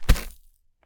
sand1.wav